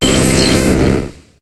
Cri de Tentacruel dans Pokémon HOME.